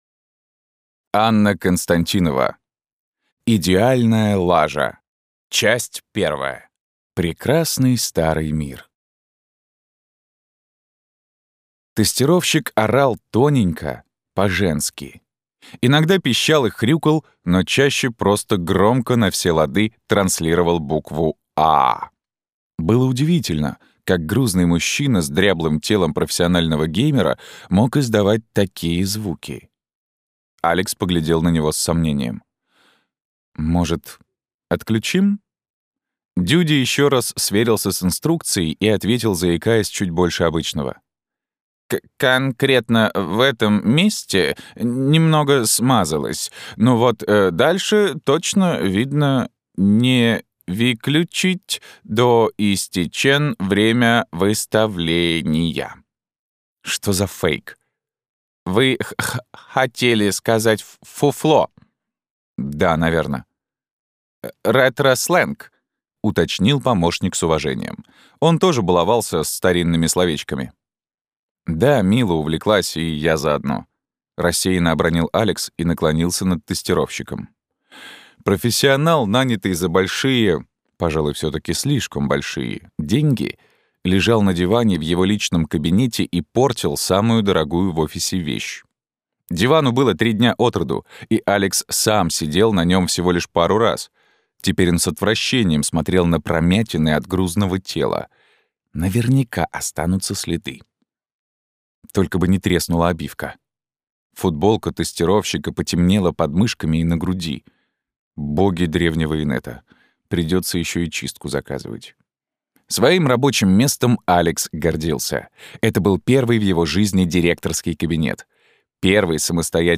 Аудиокнига Идеальная Лажа – 1.